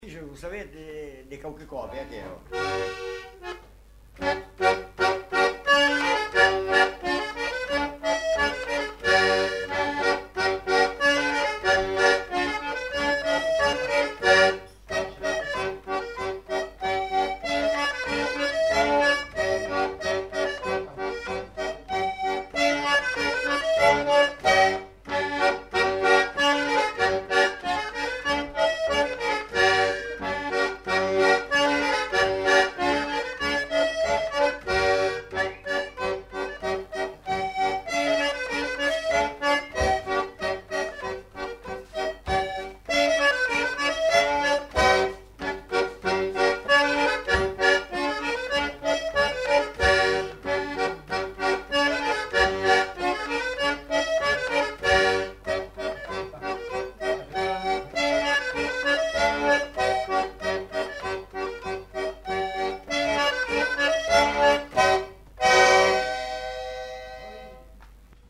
Polka
Lieu : Pyrénées-Atlantiques
Genre : morceau instrumental
Instrument de musique : accordéon diatonique
Danse : polka